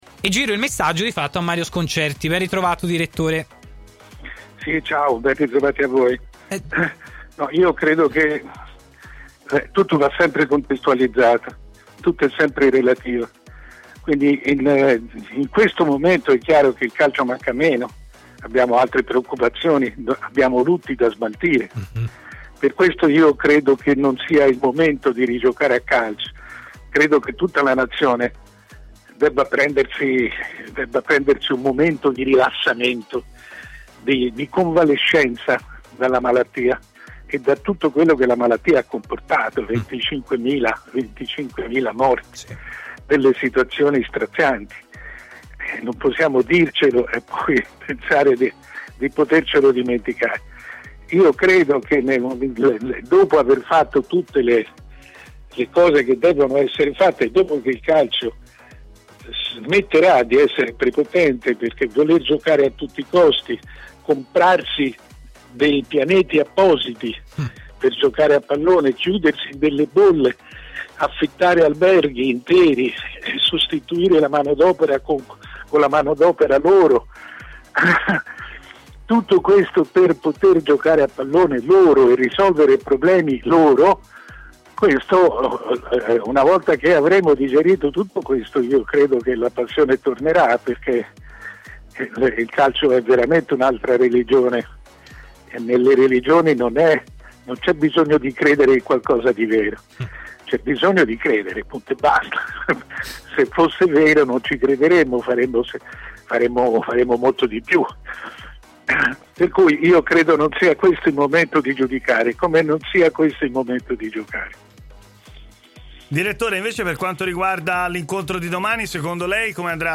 Il direttore Mario Sconcerti ha fatto il punto sullo stato del calcio italiano collegandosi in diretta con Stadio Aperto, sulle frequenze web di TMW Radio:.